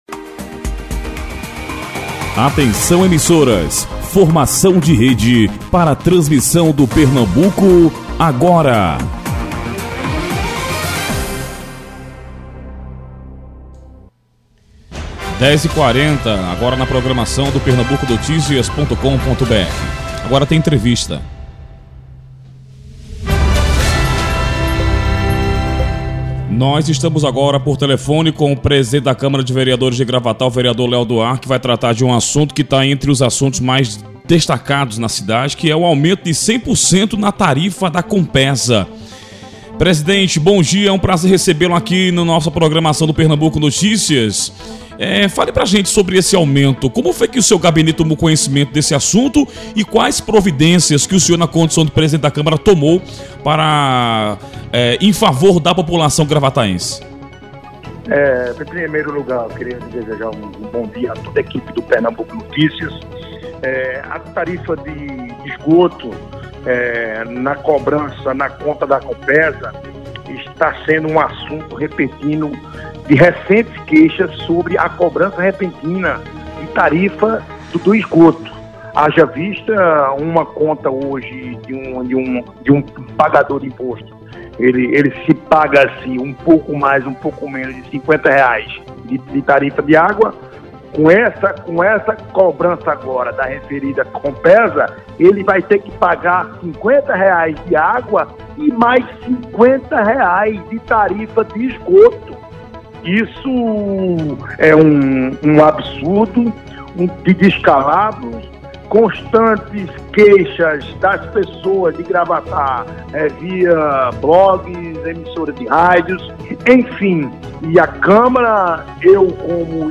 entrevista-leo-do-ar-1-Output-Stereo-Out.mp3